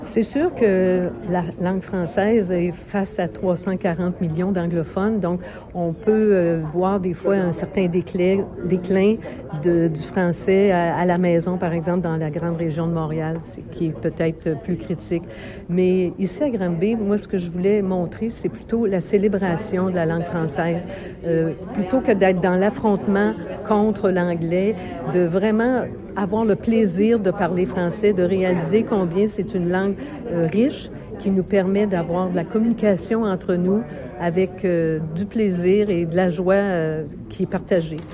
L’Association Québec-France Haute‑Yamaska a dévoilé mercredi, lors d’une conférence de presse, la programmation complète de la Semaine de la francophonie.